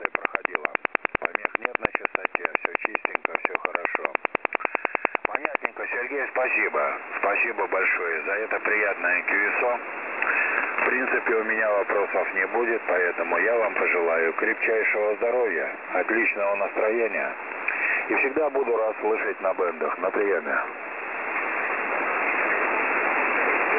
331 Кб 17.05.2014 10:45 радар?
websdr14134khz.wav